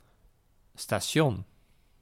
Ääntäminen
IPA: /staˈsjɔn/